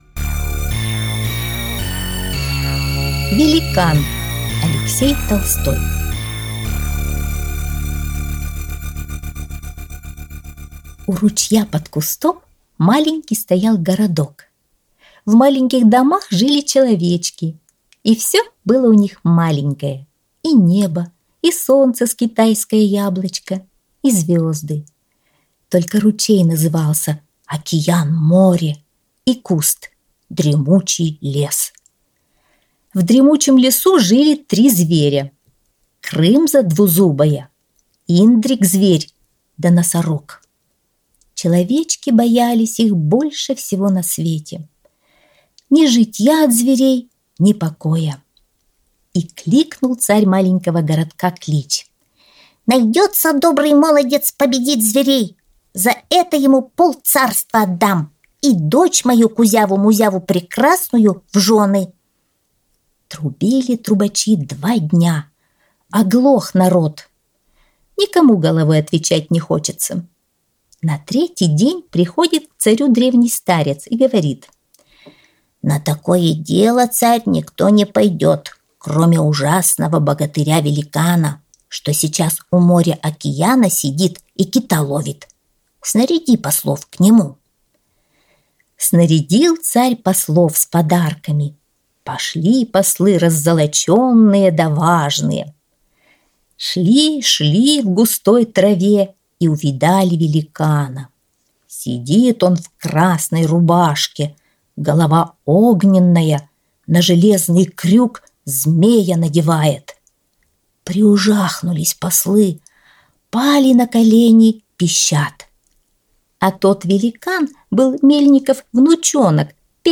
Великан - аудиосказка Алексея Толстого - слушать онлайн | Мишкины книжки